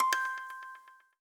Coins (1).wav